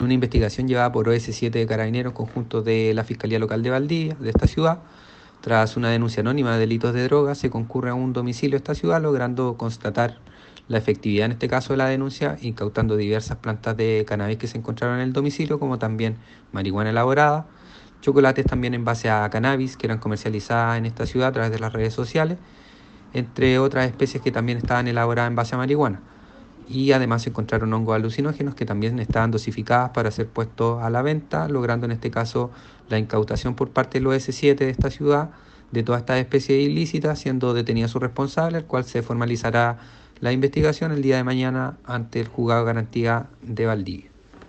Fiscal Alejandro López ….